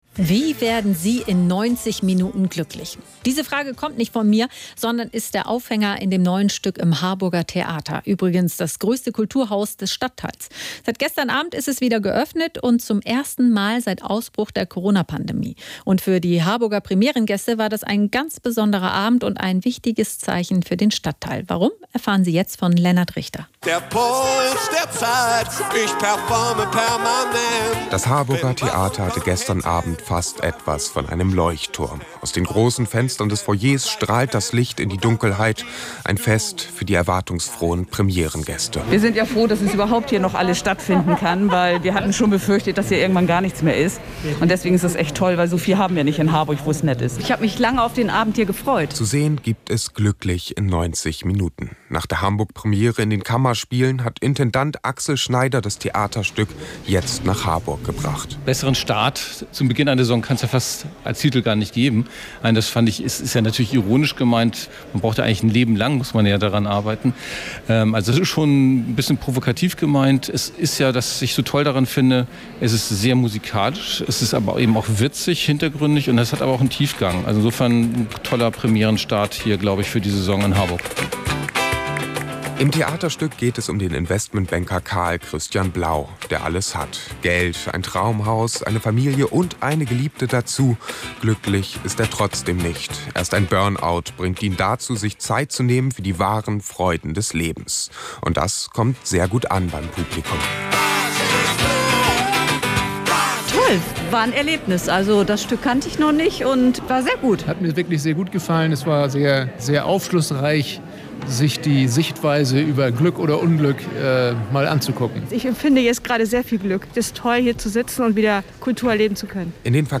Hören Sie hier den Bericht des NDR Kulturjournal über Glücklich in 90 Minuten und den Saisonstart des Harburger Theaters: